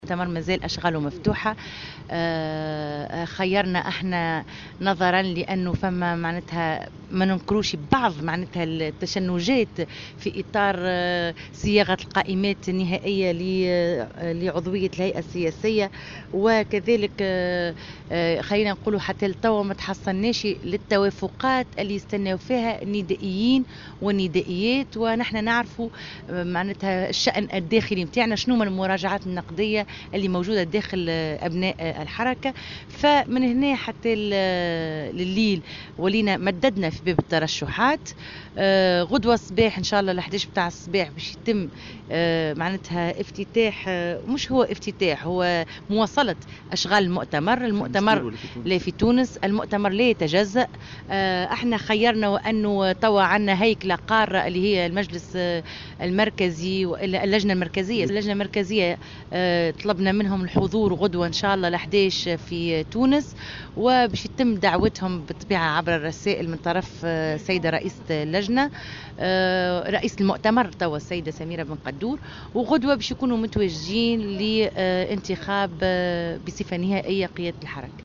وأضافت في تصريح اليوم لمراسل "الجوهرة أف أم" أنه سيتم غدا مواصلة أشغال المؤتمر في تونس العاصمة.